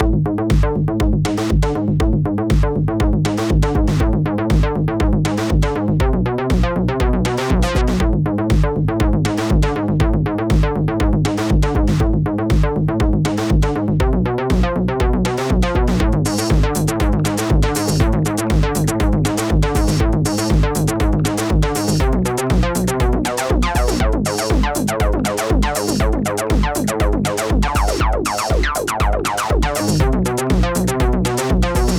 Die klassischen Wavetable Sounds sind ja ausreichend bekannt, und da ich ohnehin gerade den Vergleich Fourm vs. Pro-1 mache: Hier mal eine kurze Sequenz mit einem "klassischen" Sync-Bass.
Nur ein Oscillator, keine FX.